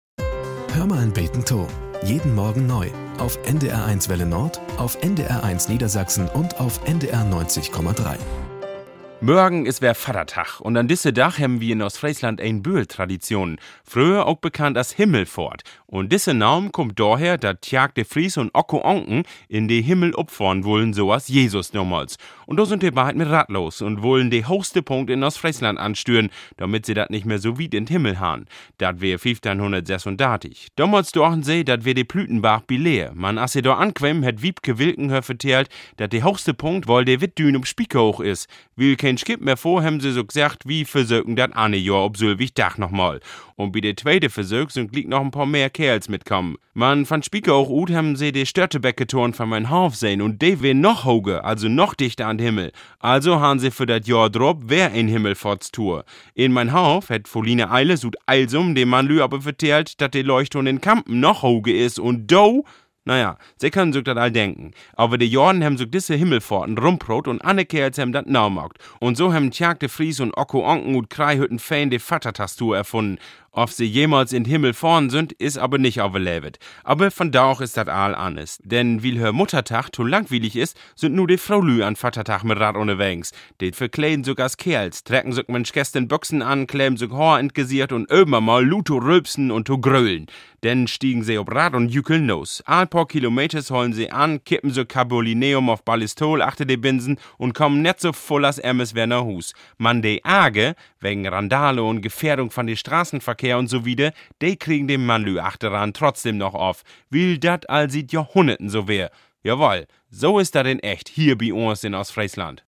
Nachrichten - 10.08.2023